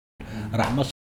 ou ra7ma
rachma_prononciation.mp3